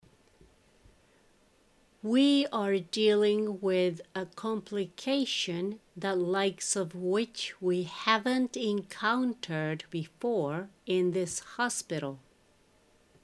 ゆっくり：